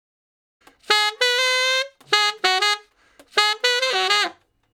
068 Ten Sax Straight (Ab) 24.wav